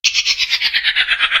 spider_dies.ogg